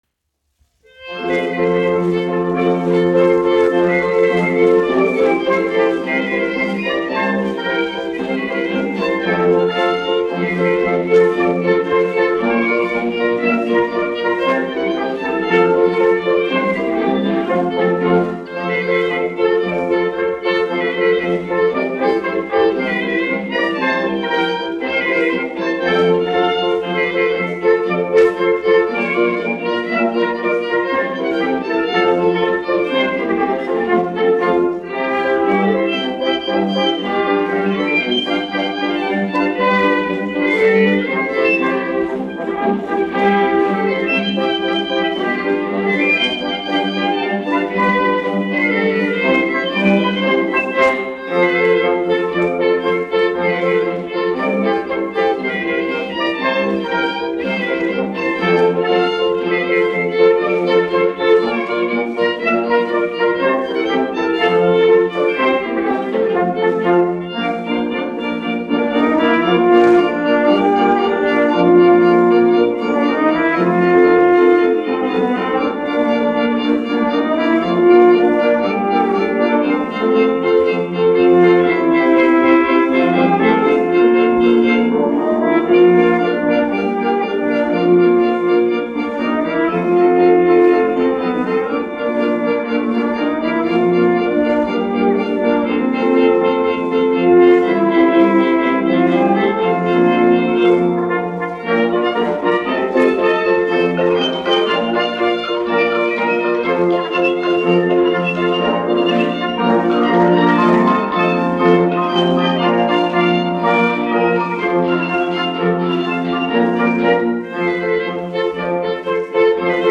1 skpl. : analogs, 78 apgr/min, mono ; 25 cm
Polkas
Skaņuplate
Latvijas vēsturiskie šellaka skaņuplašu ieraksti (Kolekcija)